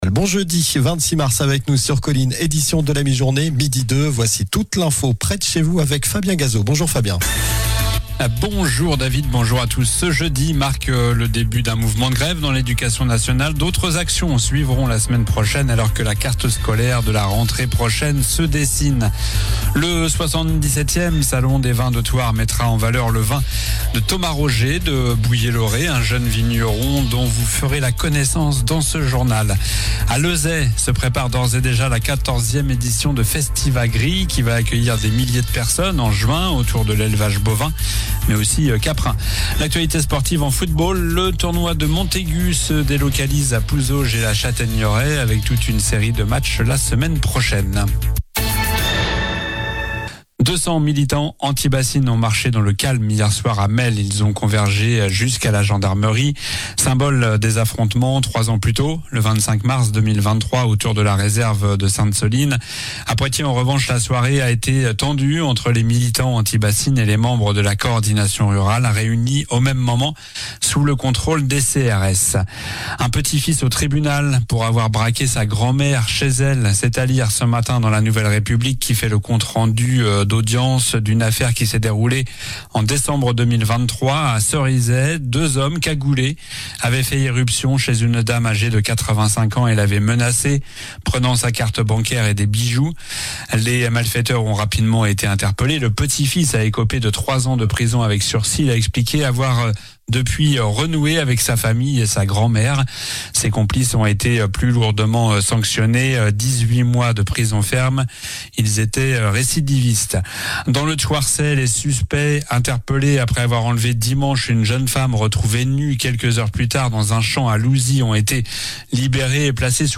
Journal du jeudi 26 mars (midi)